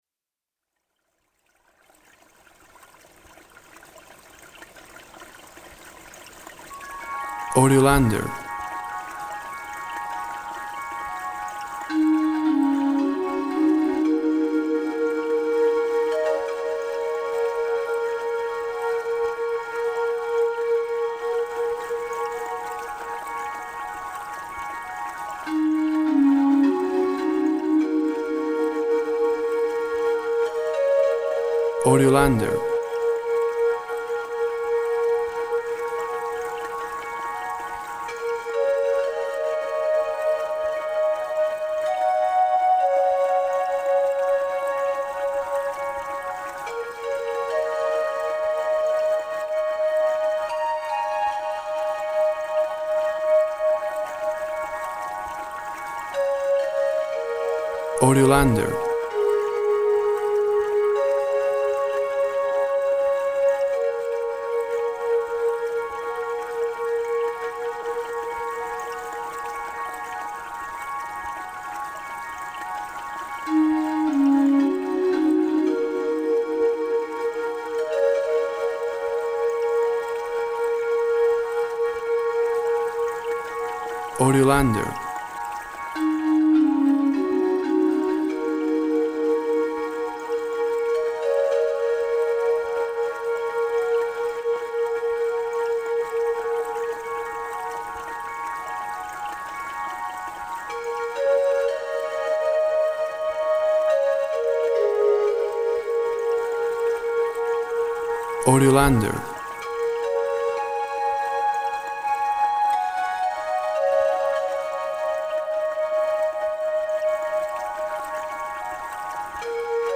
A bubbling spring inspires a wooden flute song.
Tempo (BPM) 48